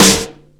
• Verby Snare Sample D# Key 96.wav
Royality free snare sound tuned to the D# note. Loudest frequency: 3799Hz
verby-snare-sample-d-sharp-key-96-tLK.wav